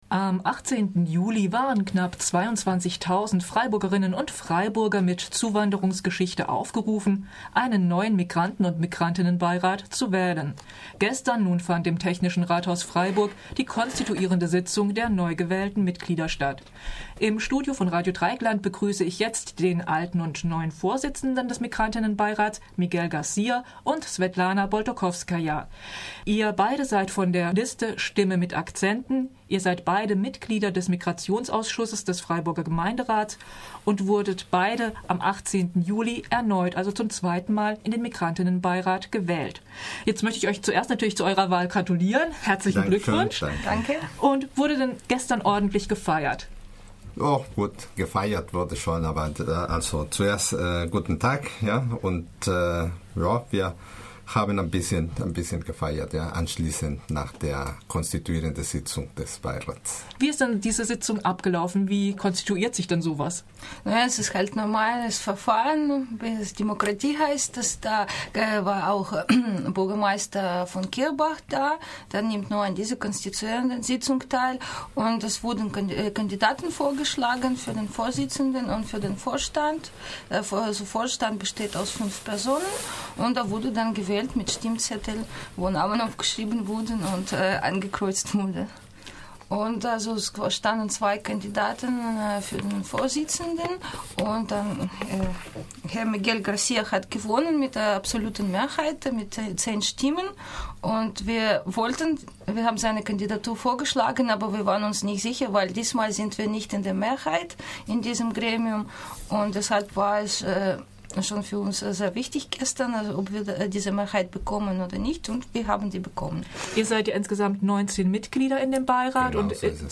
Möglichts viel live und mit Studiogästen.